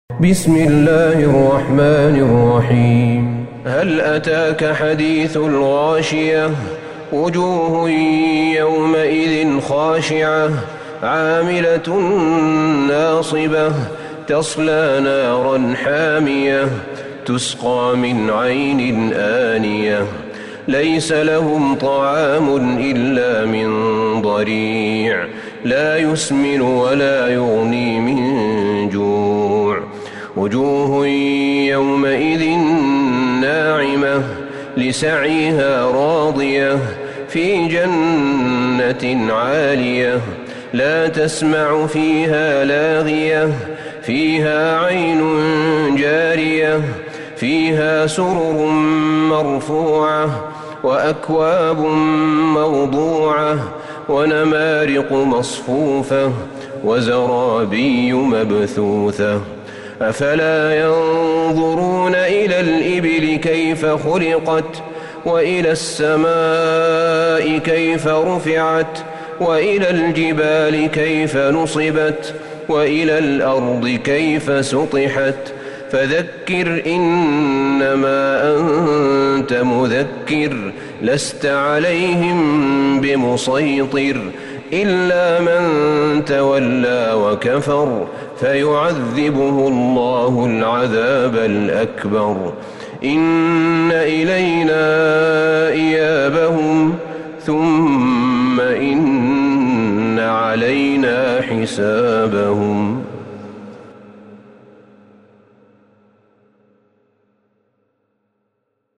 سورة الغاشية Surat Al-Ghashiyah > مصحف الشيخ أحمد بن طالب بن حميد من الحرم النبوي > المصحف - تلاوات الحرمين